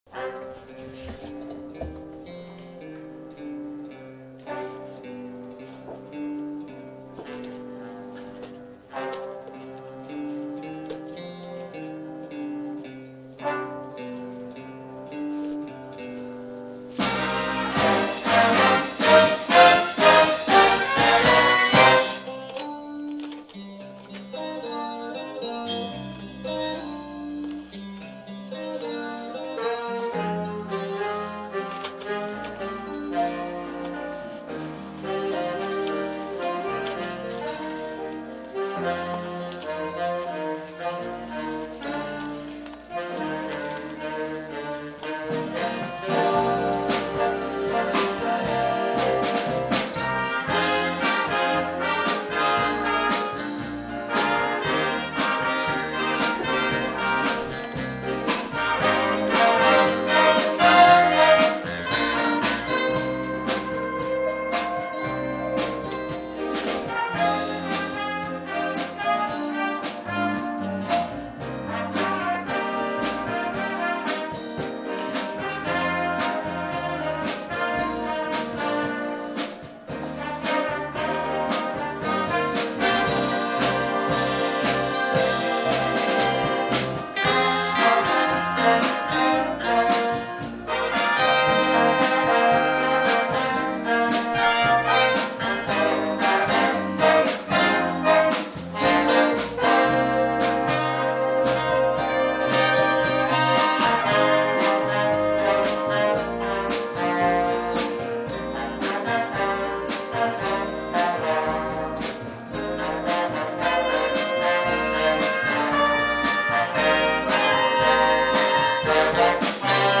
Aufnahmen einer Big Band Probe an diesem Stück sind hier zu hören.